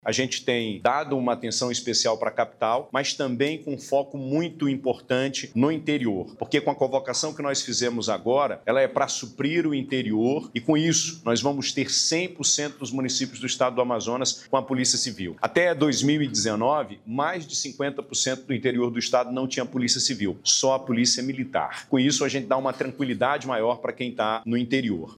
Durante a solenidade, o chefe do Executivo Estadual disse que o novo efetivo vai atuar em cidades do interior aonde antes não tinha presença da Polícia Civil.